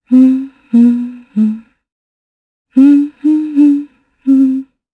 Isolet-Vox_Hum_jp.wav